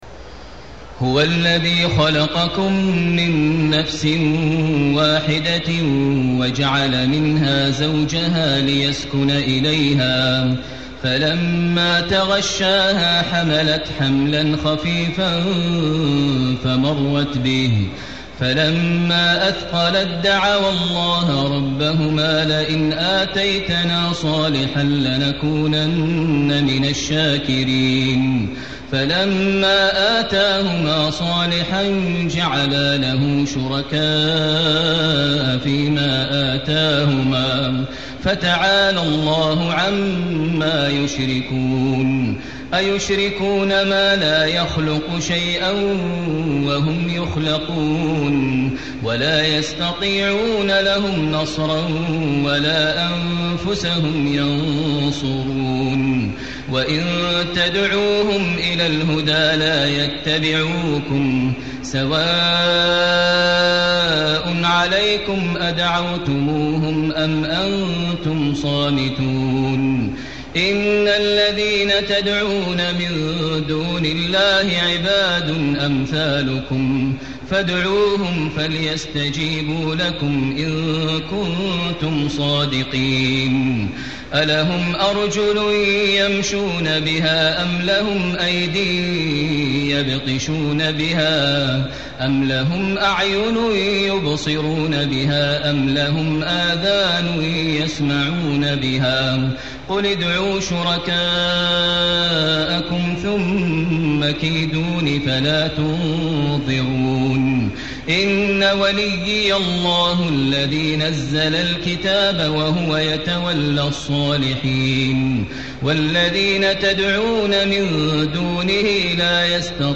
تهجد ليلة 29 رمضان 1435هـ من سورتي الأعراف (189-206) و الأنفال (1-40) Tahajjud 29 st night Ramadan 1435H from Surah Al-A’raf and Al-Anfal > تراويح الحرم المكي عام 1435 🕋 > التراويح - تلاوات الحرمين